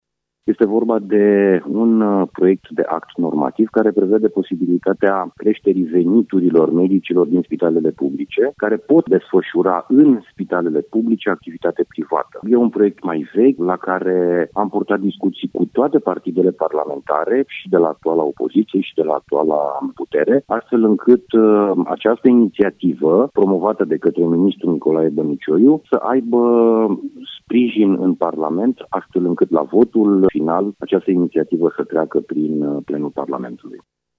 Un alt proiect important, spune deputatul, este cel privind creşterea veniturilor medicilor din spitalele publice: